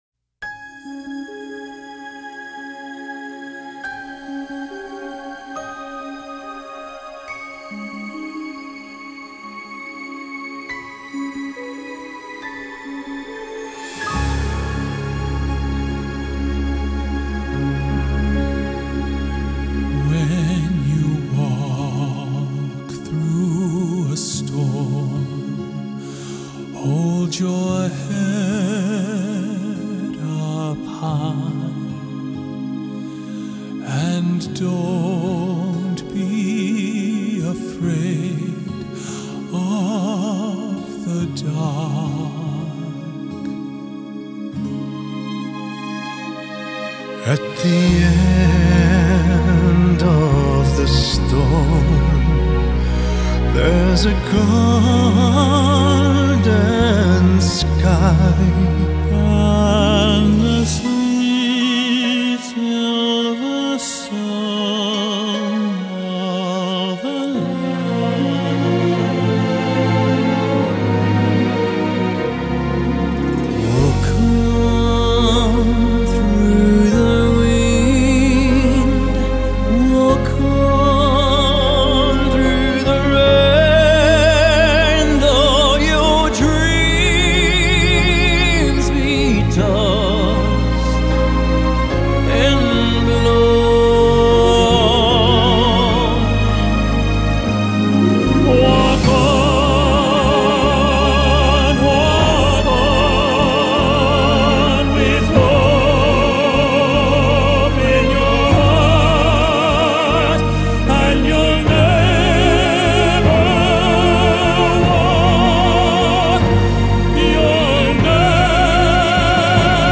Genre: Pop, Crossover, Musical